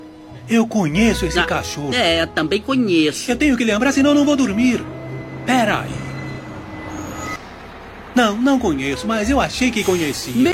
eu conheco esse cachorro Meme Sound Effect